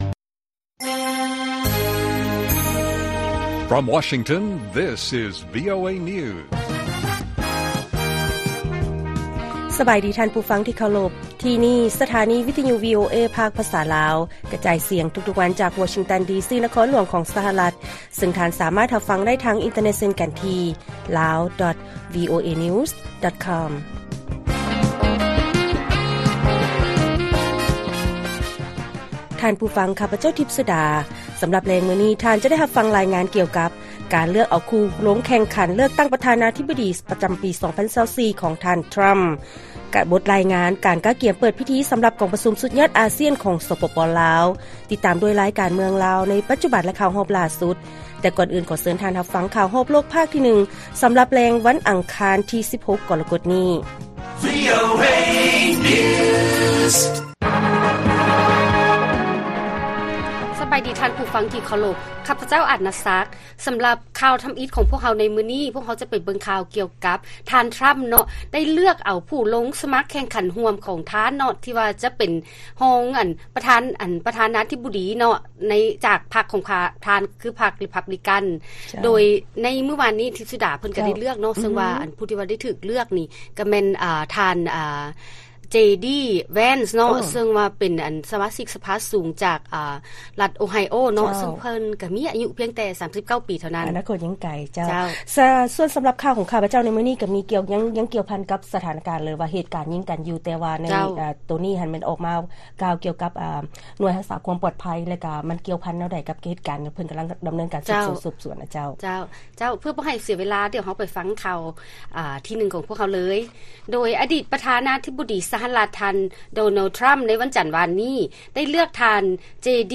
ລາຍການກະຈາຍສຽງຂອງວີໂອເອ ລາວ: ທ່ານ ເຈ.ດີ. ແວນສ໌ ຖືກເລືອກເປັນຜູ້ຮ່ວມລົງແຂ່ງຂັນເອົາຕໍ່າແໜ່ງຮອງປະທານາທິບໍດີ ຮ່ວມກັບທ່ານທຣໍາ.